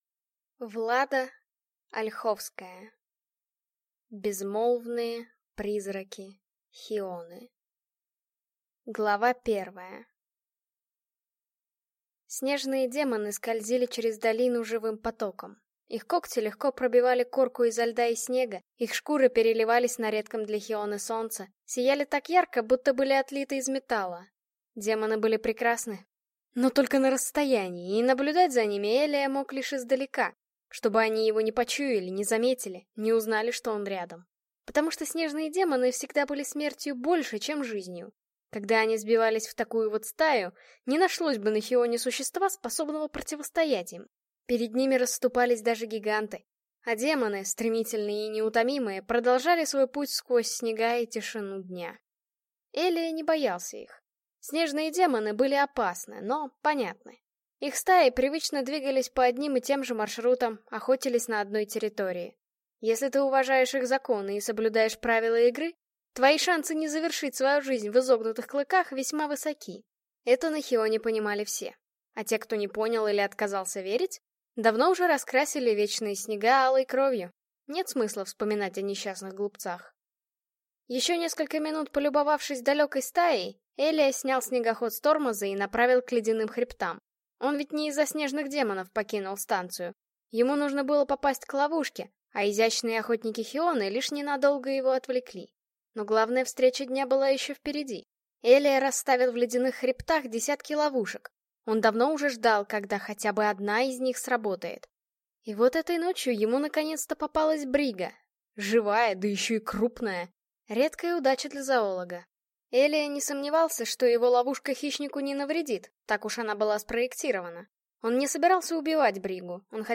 Аудиокнига Безмолвные призраки Хионы | Библиотека аудиокниг